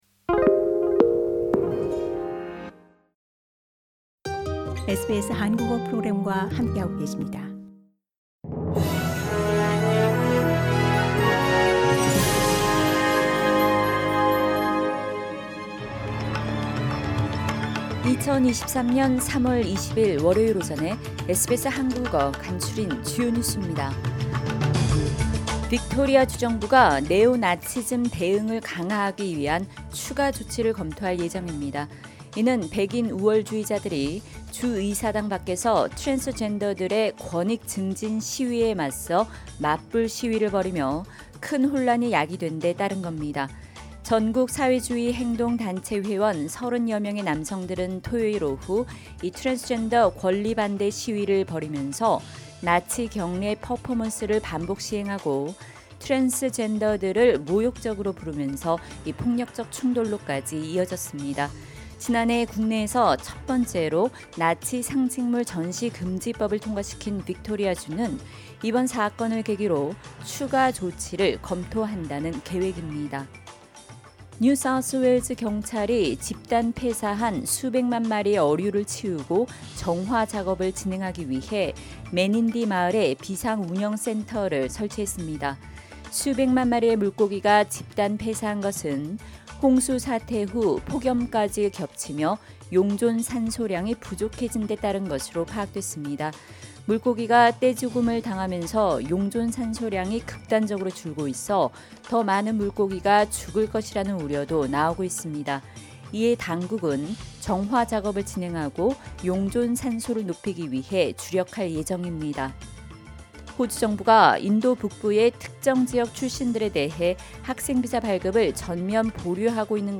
SBS 한국어 아침 뉴스: 2023년 3월 20일 월요일
2023년 3월 20일 월요일 아침 SBS 한국어 간추린 주요 뉴스입니다.